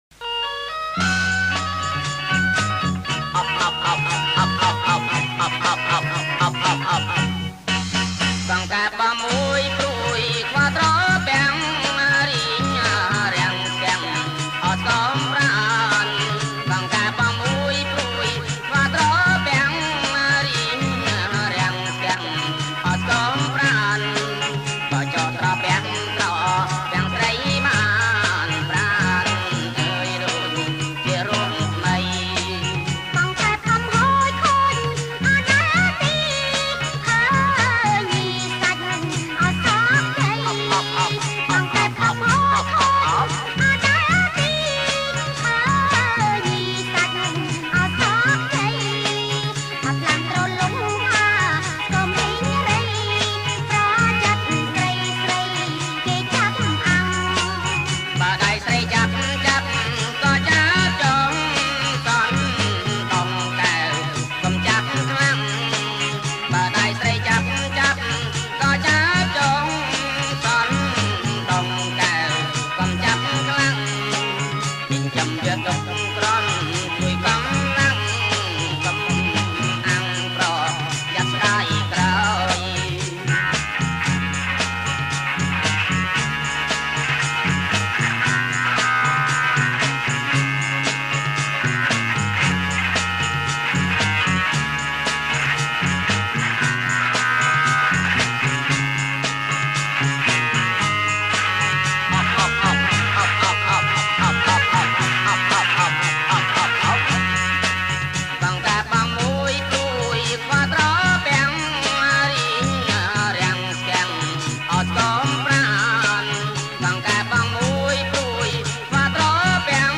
• ប្រគំជាចង្វាក់ សារ៉ាវ៉ាន់